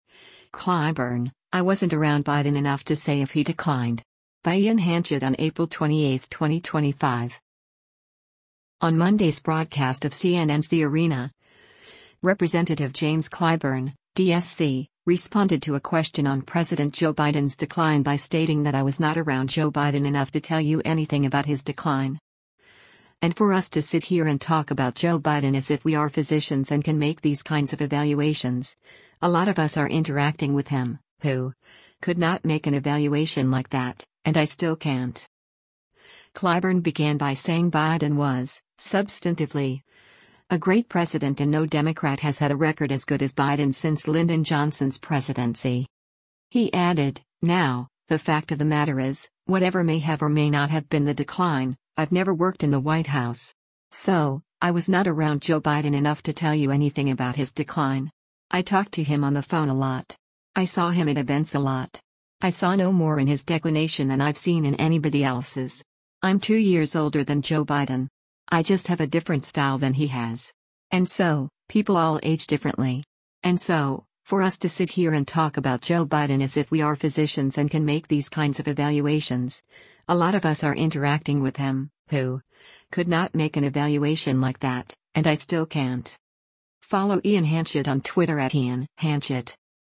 On Monday’s broadcast of CNN’s “The Arena,” Rep. James Clyburn (D-SC) responded to a question on President Joe Biden’s decline by stating that “I was not around Joe Biden enough to tell you anything about his decline.”